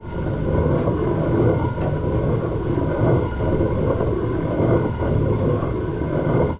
ore-powderizer.ogg